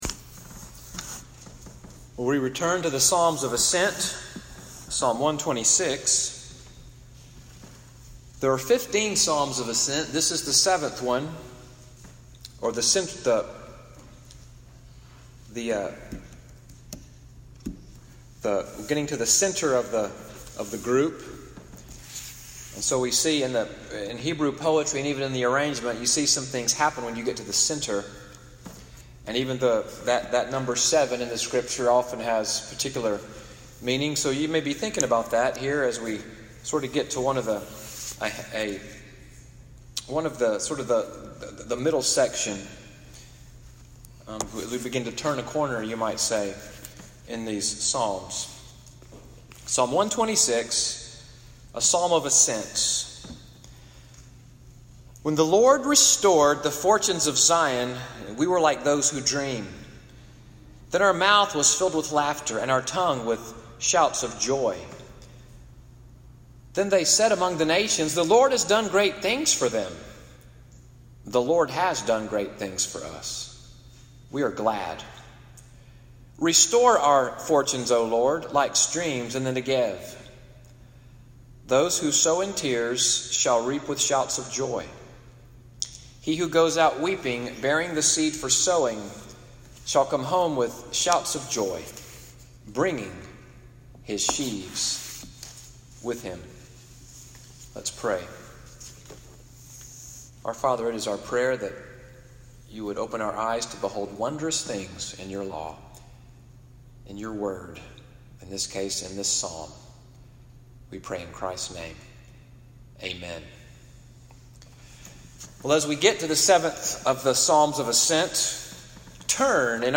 Evening Worship at NCPC-Selma, audio from the sermon, “The LORD, the Restorer,” (23:14) preached June 17, 2018.